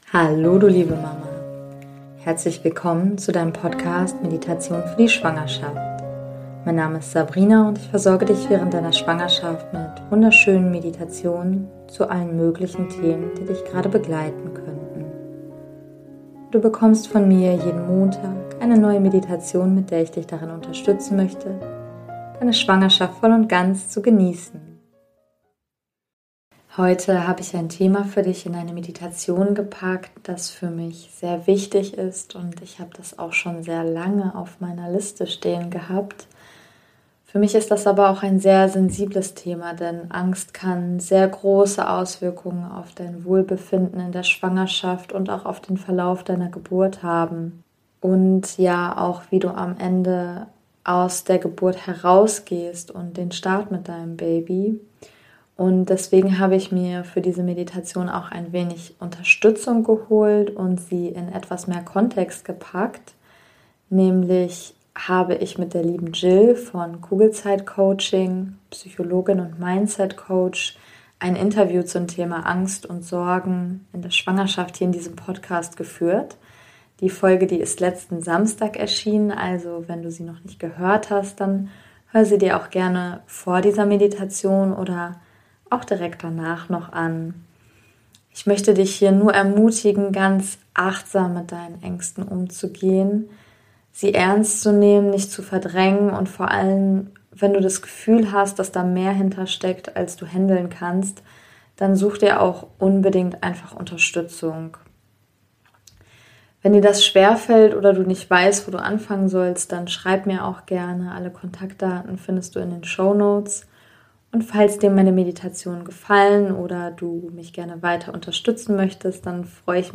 #021 - Meditation Angst und Sorgen in der Schwangerschaft ~ Meditationen für die Schwangerschaft und Geburt - mama.namaste Podcast